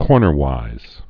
(kôrnər-wīz) also cor·ner·ways (-wāz)